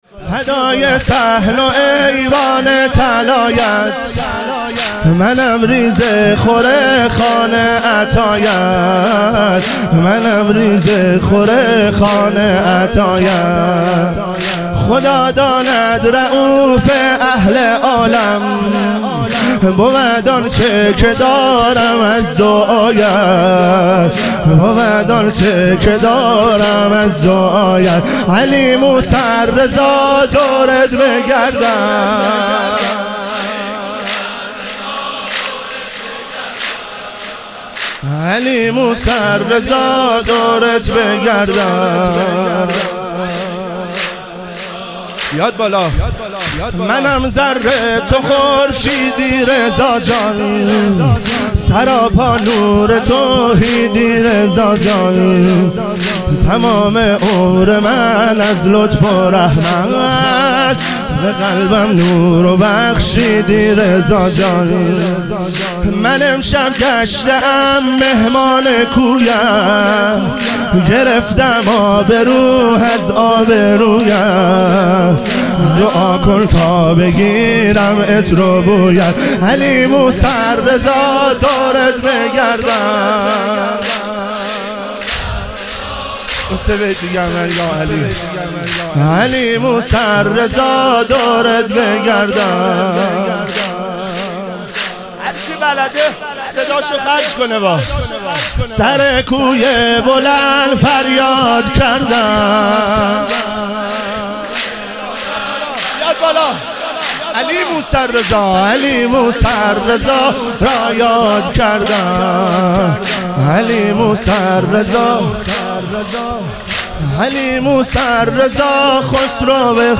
ظهور وجود مقدس امام رضا علیه السلام - تک